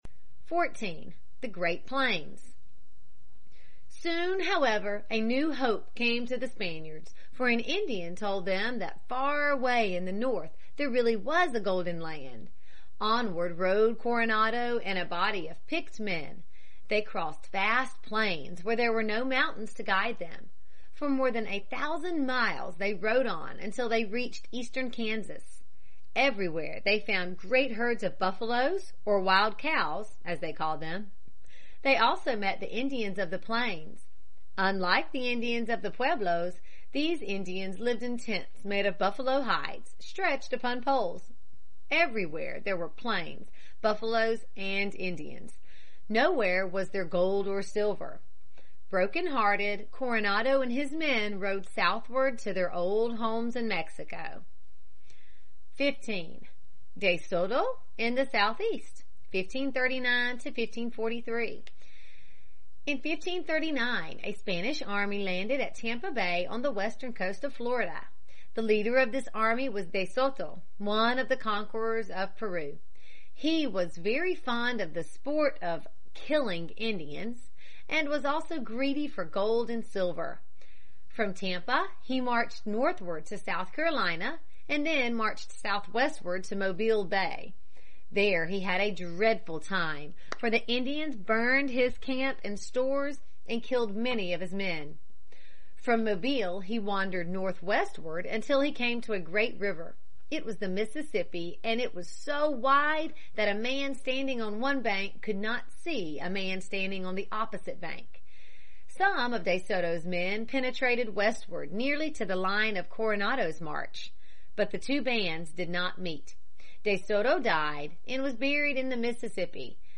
在线英语听力室美国学生历史 第5期:美国的西班牙和法国先驱(2)的听力文件下载,这套书是一本很好的英语读本，采用双语形式，配合英文朗读，对提升英语水平一定更有帮助。